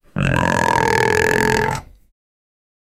long-loud-pig-grunt-of-w3gkskal.wav